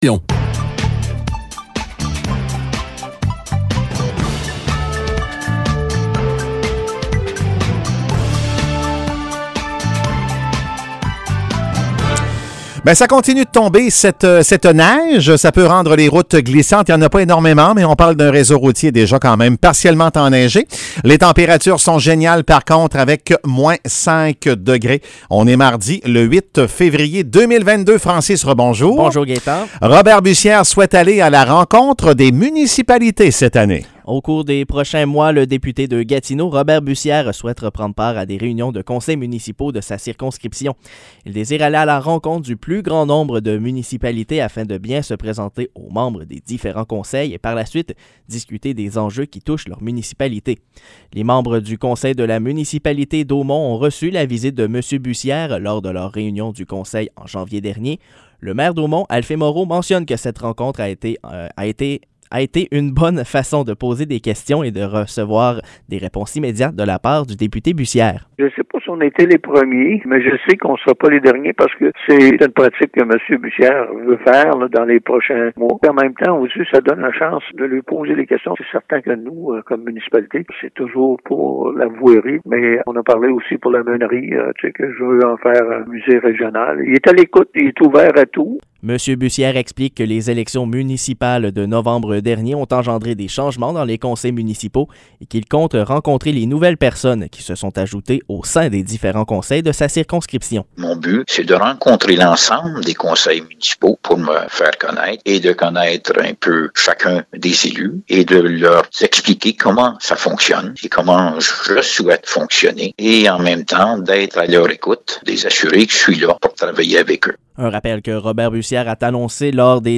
Nouvelles locales - 8 février 2022 - 8 h